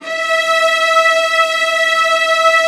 VIOLAS F#5-L.wav